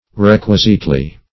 [1913 Webster] -- Req"ui*site*ly, adv.